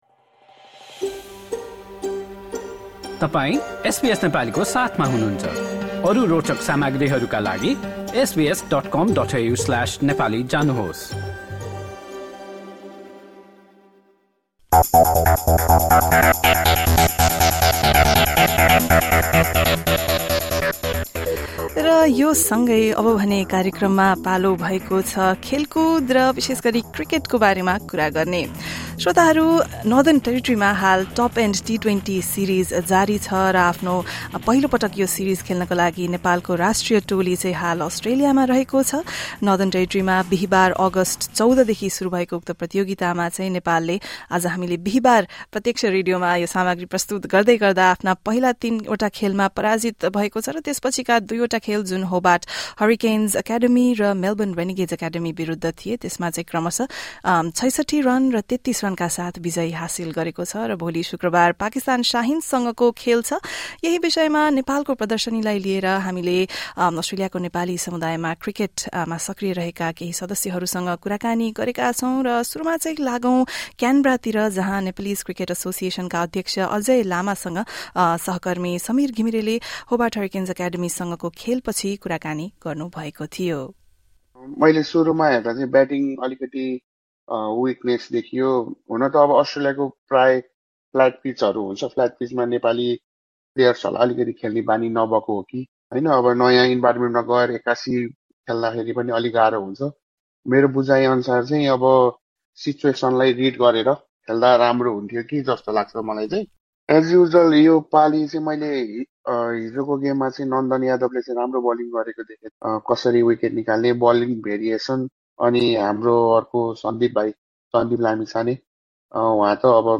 एसबीएस नेपालीले गरेको कुराकानी सुन्नुहोस्।